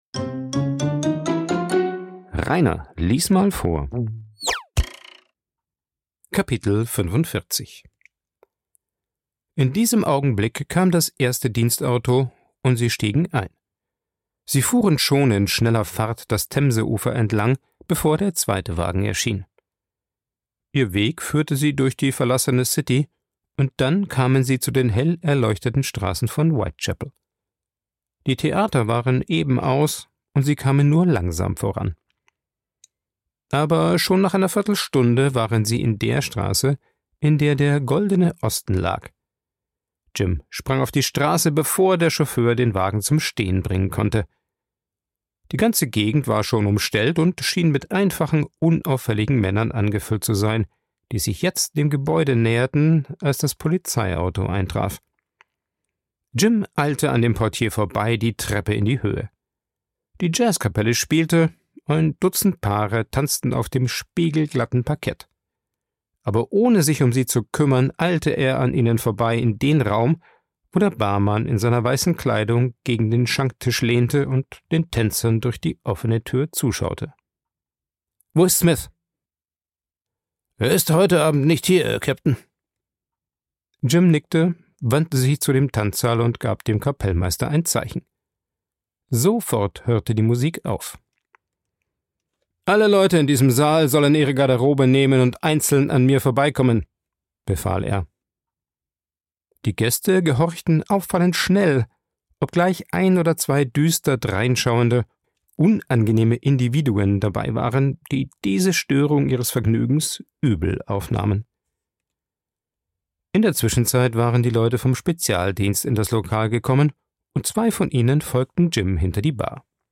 Ein Vorlese Podcast